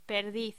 Locución: Perdiz
voz